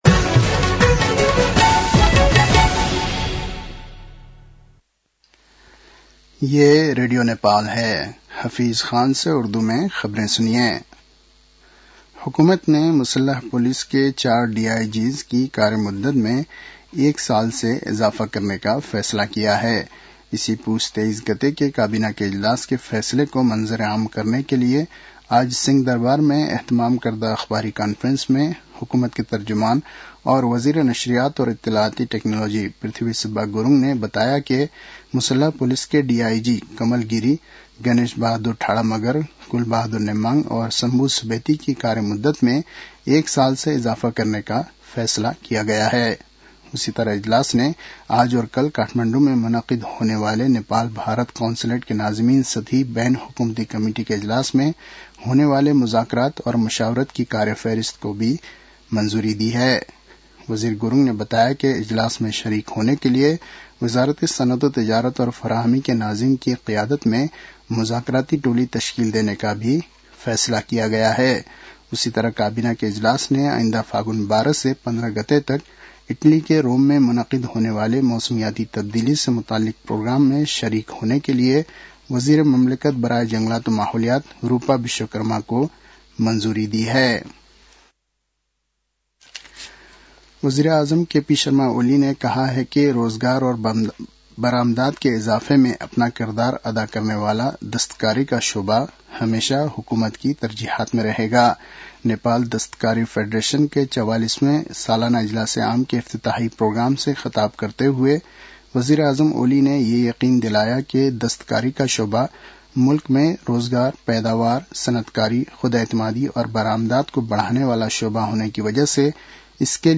उर्दु भाषामा समाचार : २७ पुष , २०८१